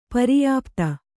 ♪ pariyāpta